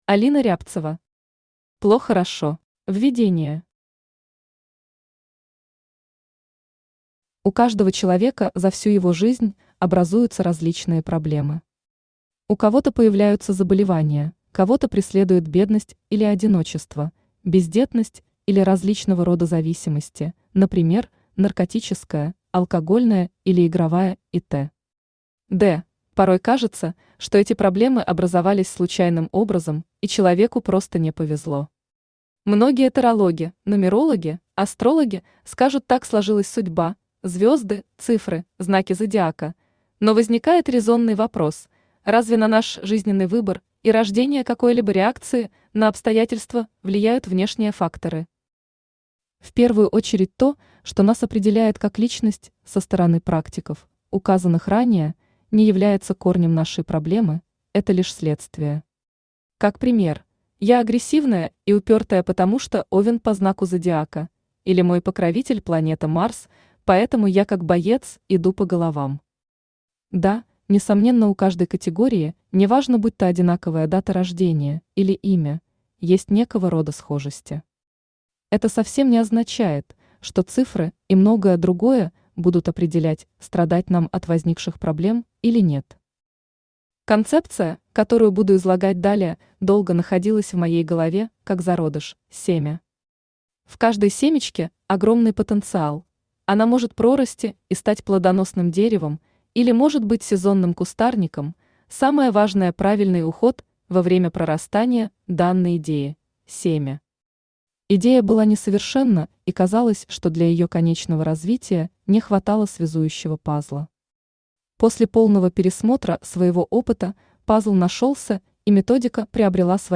Аудиокнига ПлоХОрошо | Библиотека аудиокниг
Aудиокнига ПлоХОрошо Автор Алина Андреевна Рябцева Читает аудиокнигу Авточтец ЛитРес.